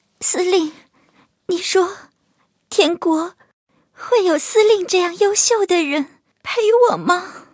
M2中坦被击毁语音.OGG